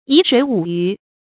沂水舞雩 注音： ㄧˊ ㄕㄨㄟˇ ㄨˇ ㄧㄩˊ 讀音讀法： 意思解釋： 謂知時處世，逍遙游樂。